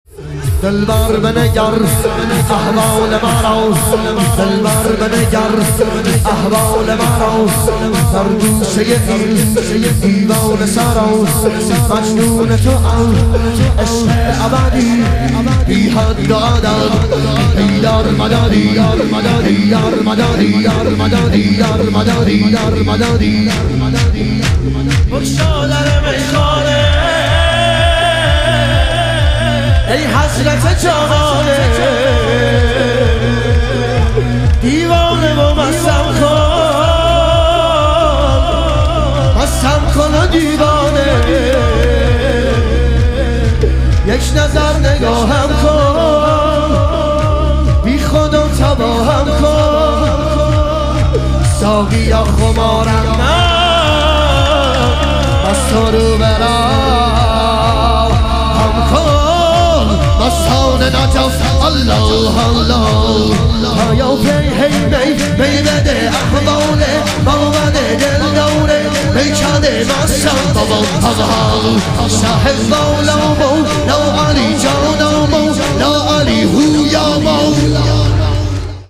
شهادت امام صادق علیه السلام - شور